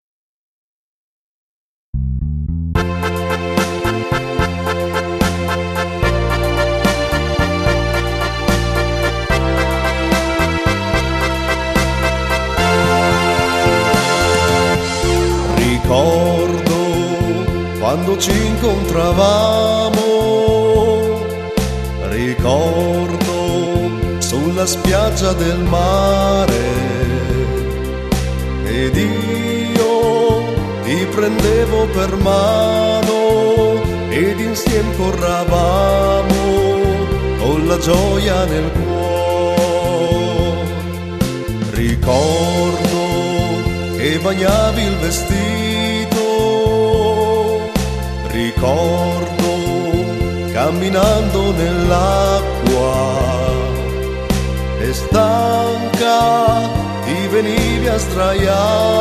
Slow moderato
presenta 6 canzoni ballabili.